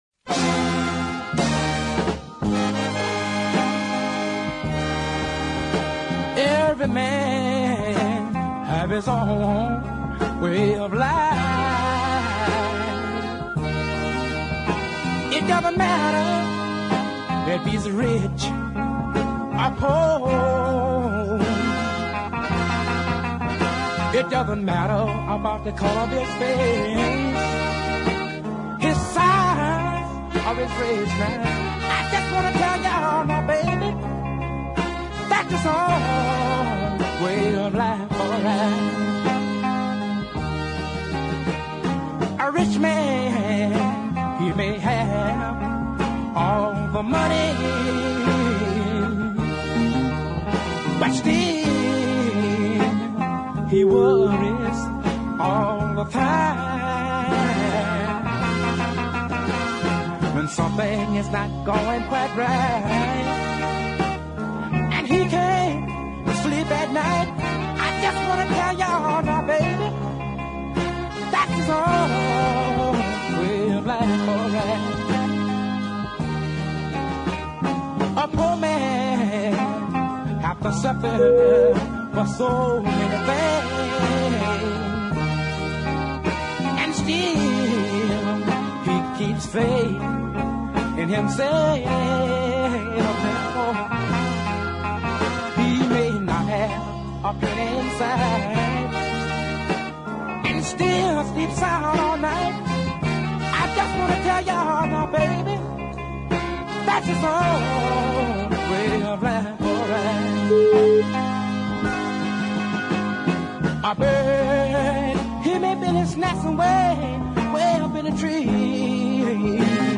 the recording studio in Bogalusa